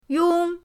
yong1.mp3